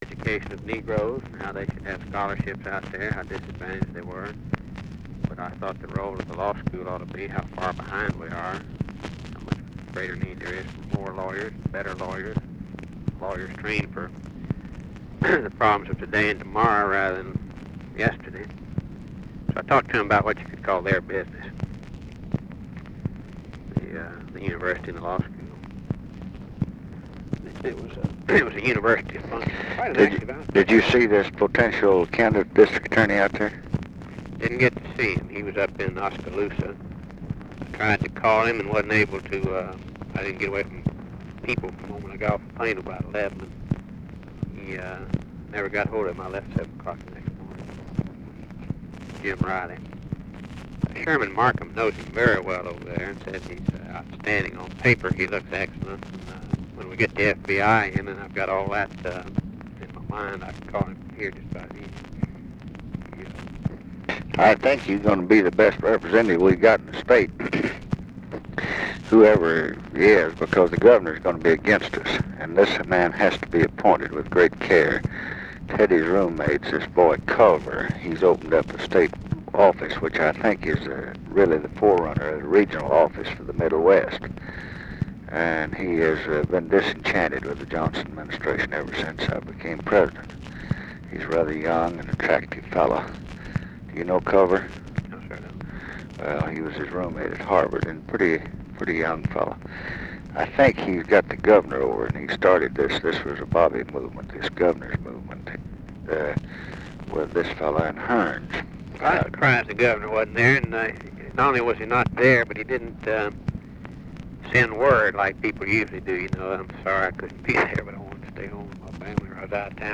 Conversation with RAMSEY CLARK, February 20, 1967
Secret White House Tapes